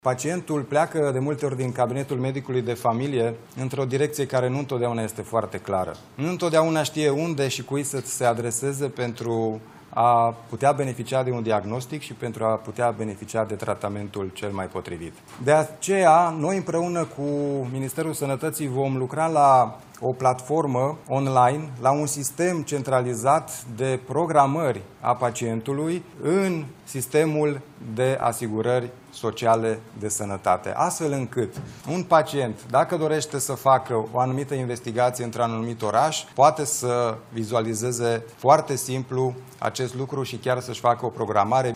Declarațiile au fost făcute în conferința de presă maraton de ieri în care premierul Ilie Bolojan și ministrul sănătății – Alexandru Rogobete – au prezentat o parte din măsurile care vor fi luate pentru reforma sistemului de sănătate.